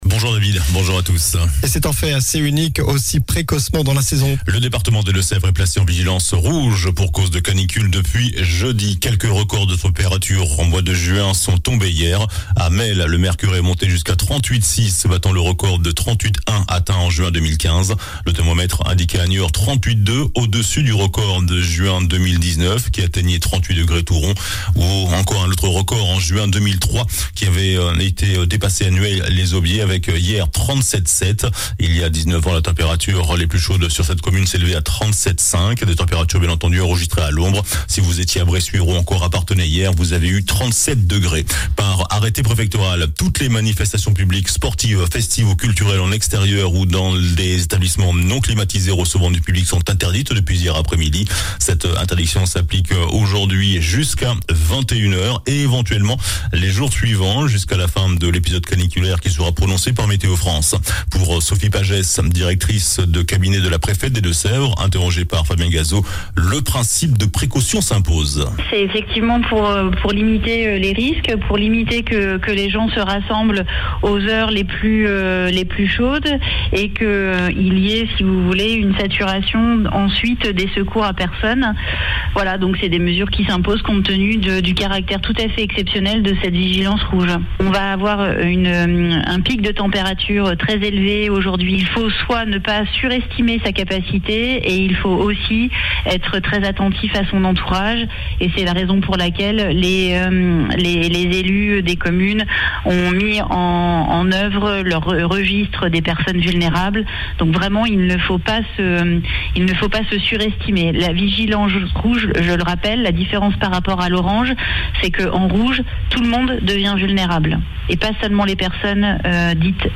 JOURNAL DU SAMEDI 18 JUIN